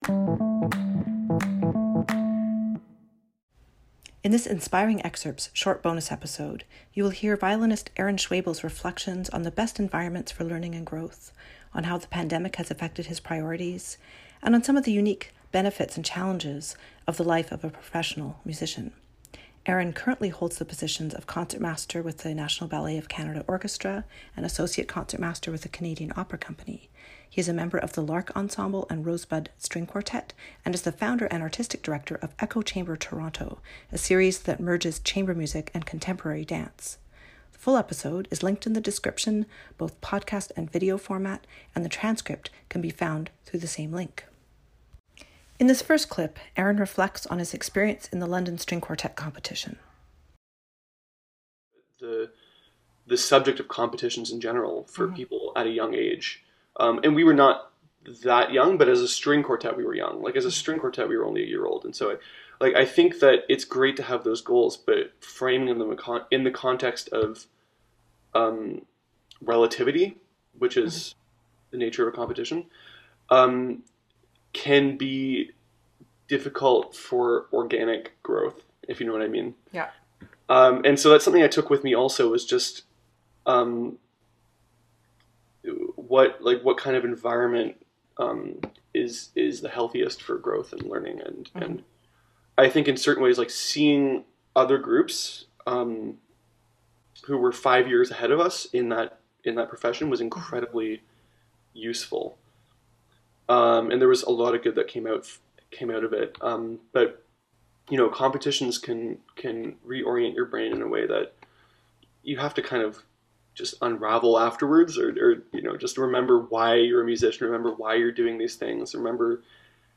Conversations with Musicians
In this Inspiring Excerpts short bonus episode, you will hear violinist